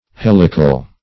Helical \Hel"i*cal\, a. [From Helix.]